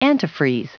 Prononciation du mot antifreeze en anglais (fichier audio)